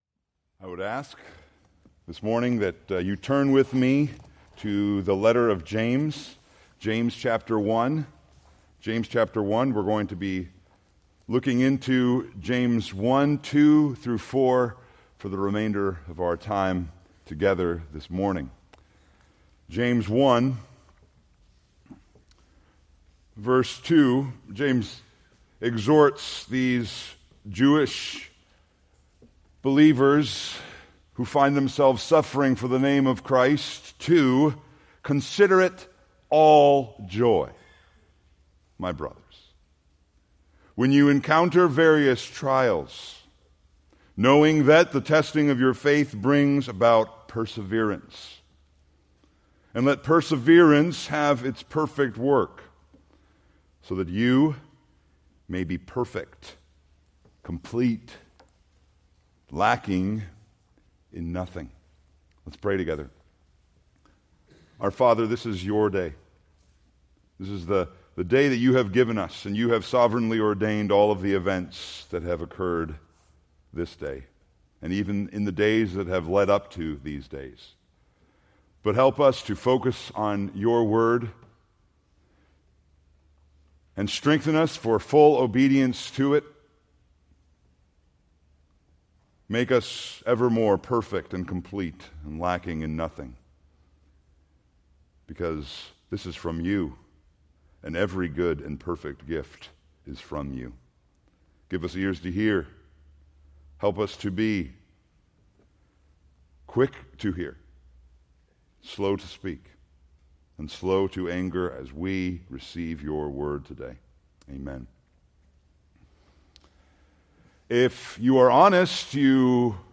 Preached July 14, 2024 from James 1:2-4